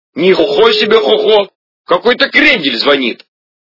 » Звуки » Люди фразы » Голос - Ни хохо себе хохо
Звук Голос - Ни хохо себе хохо